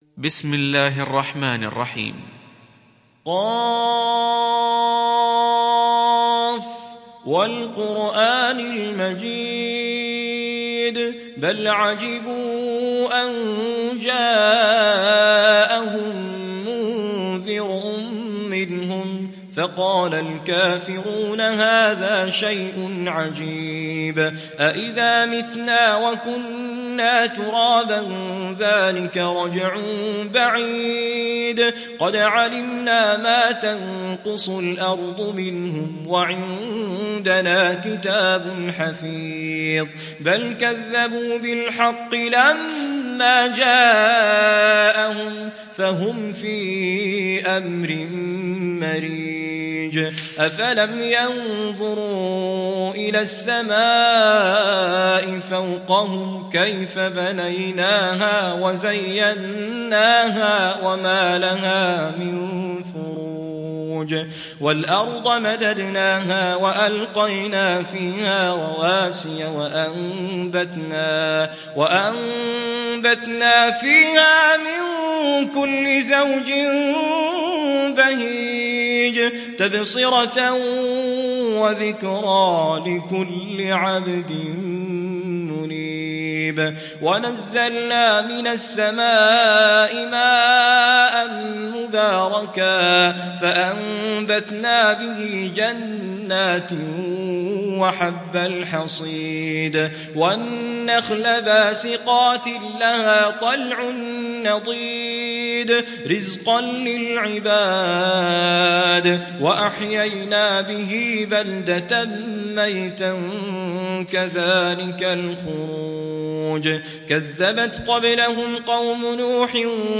(رواية حفص)